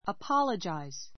apologize A2 əpɑ́lədʒaiz ア パ ろヂャイ ズ 動詞 謝 あやま る, おわびする George Washington apologized to his father for cutting down a cherry tree.